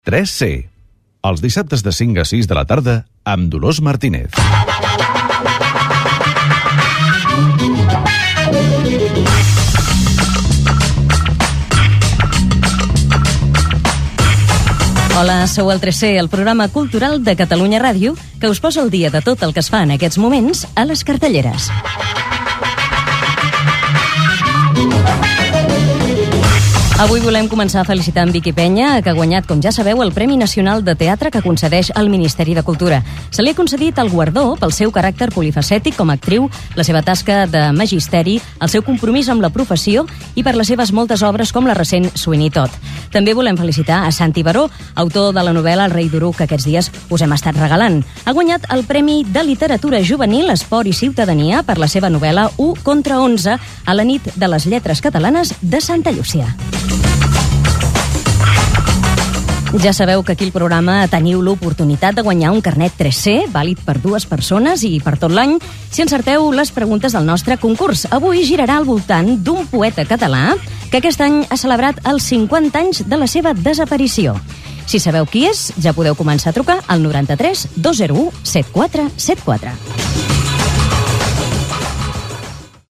Careta del programa, presentació, titulars, concurs